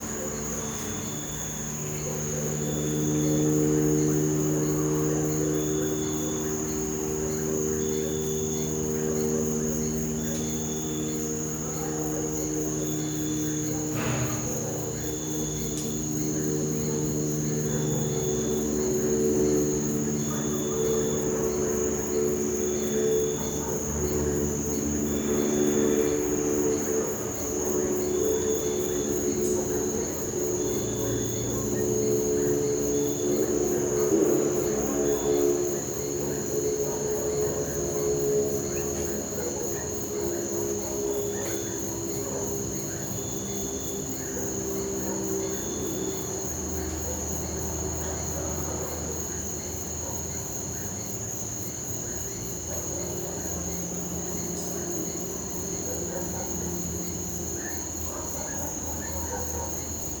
CSC-18-099-LE - Ambiencia moto longe rural gravacao dentro de casa a noite com grilos cigarras sapos cachorro longe.wav